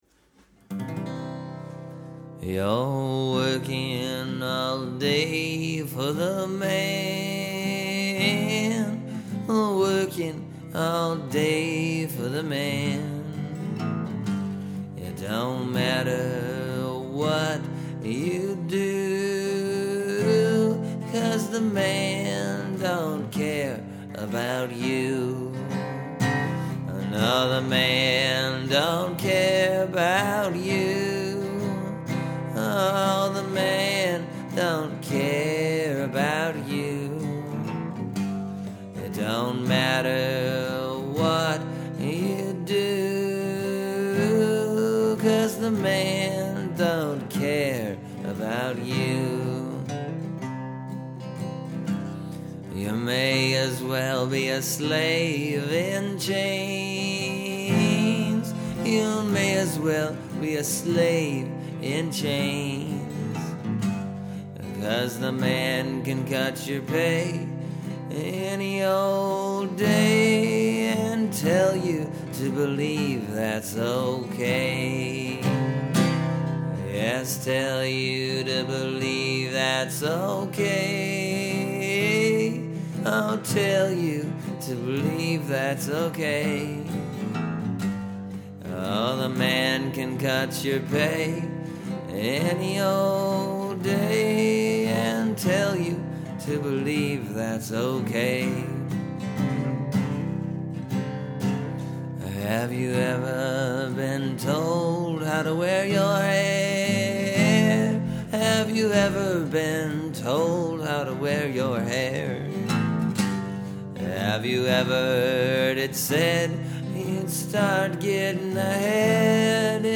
It’s a pretty straight forward tune.